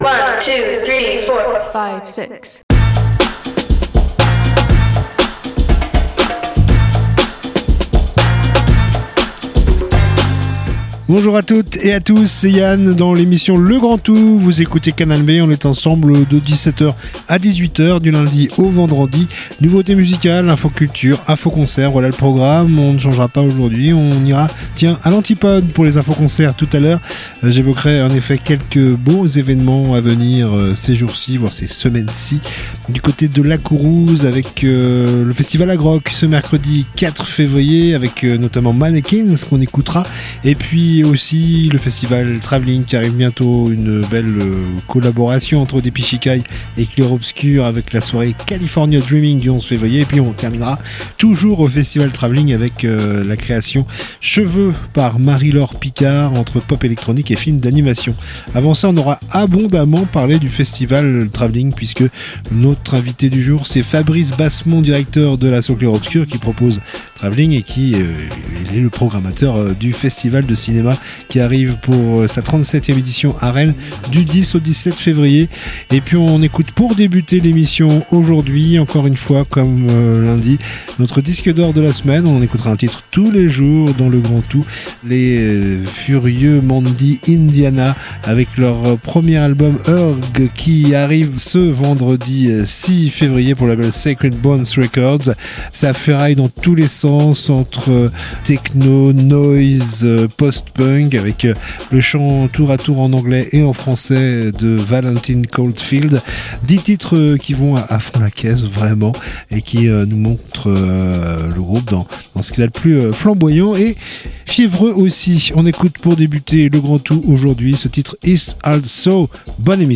itv culture Discussion